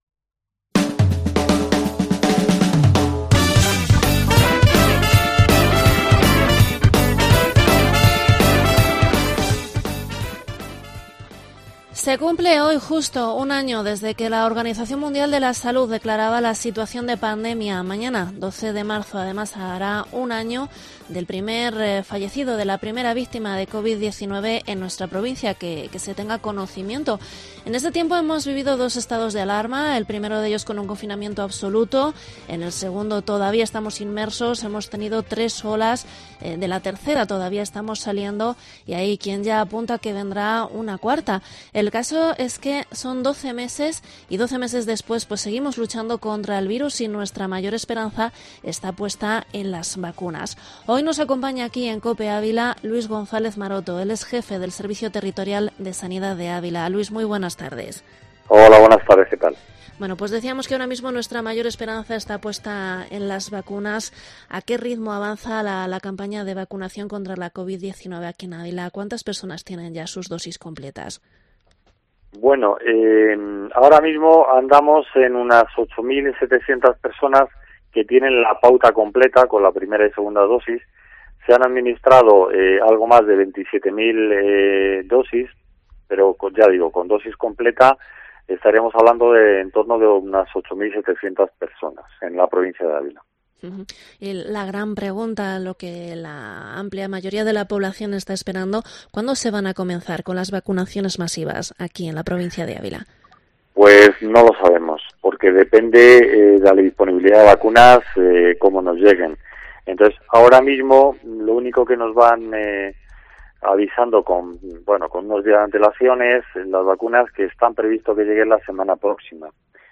Entrevista al jefe territorial del Servicio de Sanidad, Luís González Maroto